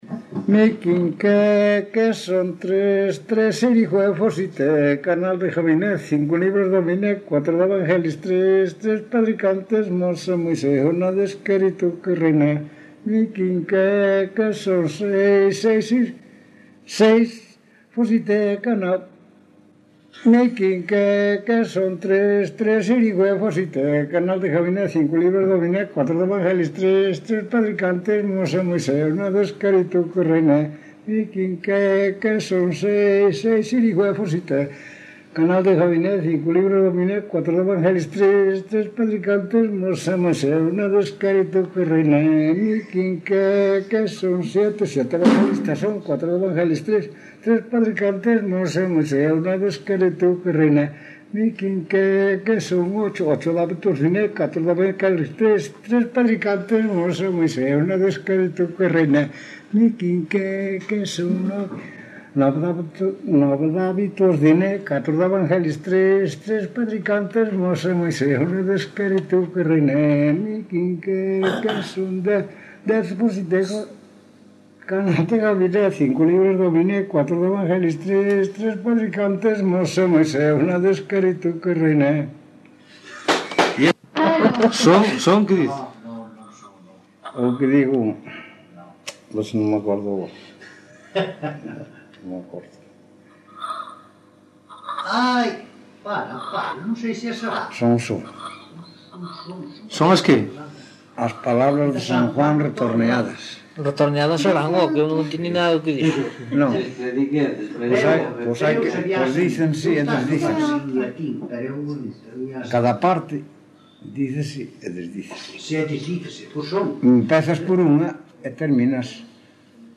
Palabras chave: oración
Tipo de rexistro: Musical
Lugar de compilación: Outeiro de Rei - Bonxe (San Mamede)
Soporte orixinal: Casete
Datos musicais Refrán
Instrumentación: Voz
Instrumentos: Voz masculina